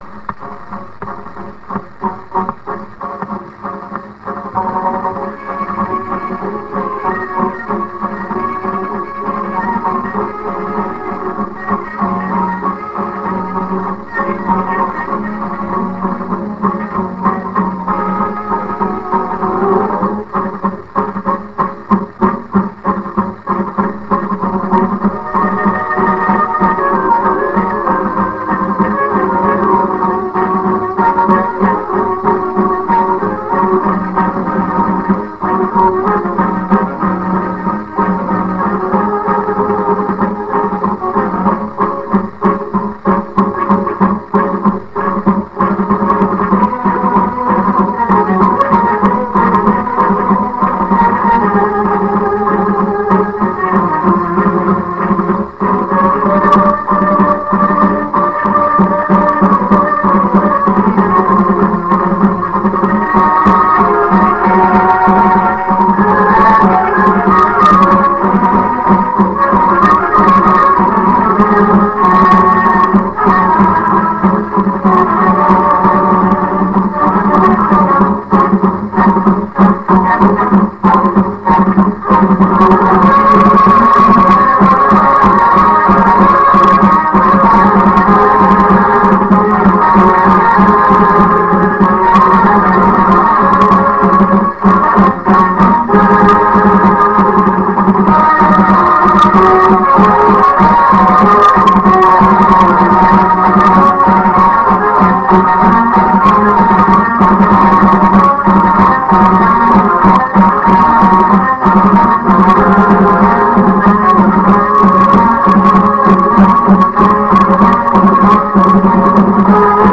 蓄音機から流れる音を録音してみました!!
ノスタルジックな雑音混じりの音楽を